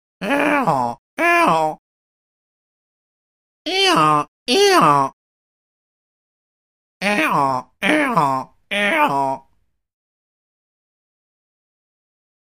Donkey Brays - 3 Effects; Donkey Brays.